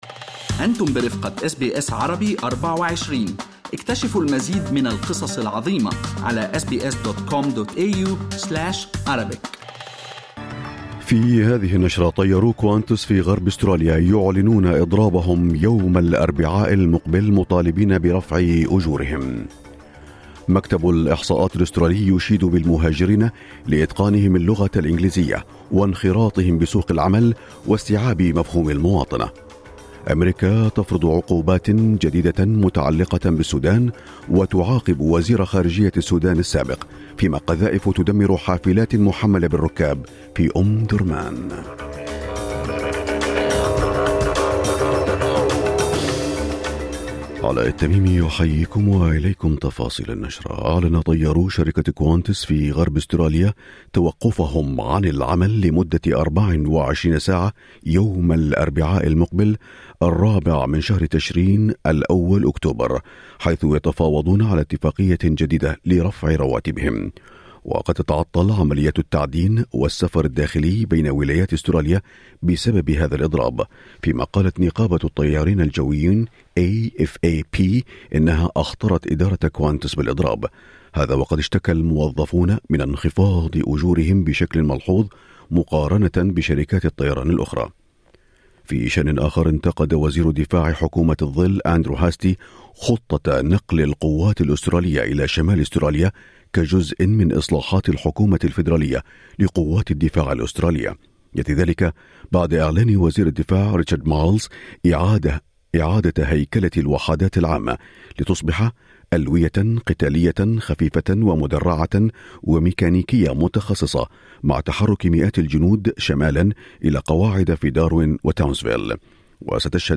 نشرة أخبار الصباح 29/9/2023